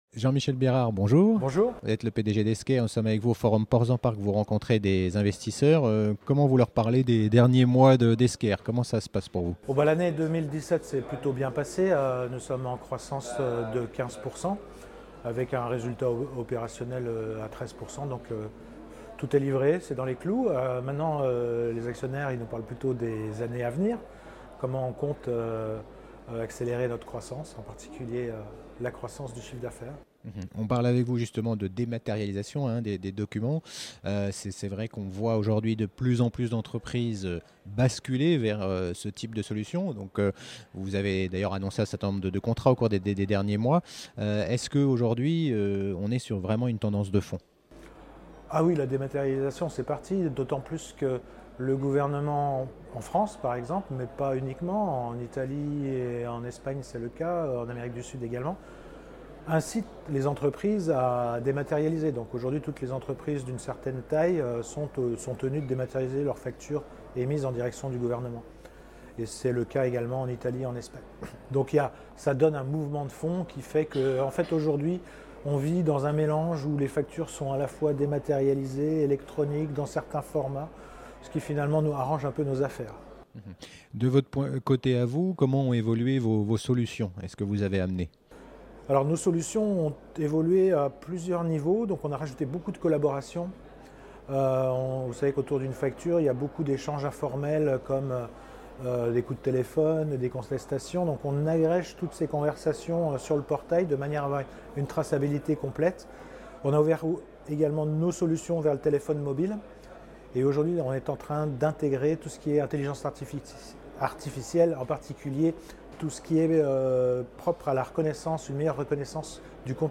était partenaire de la Conférence Midcap Portzamparc
a interviewé plusieurs dirigeants d’entreprises.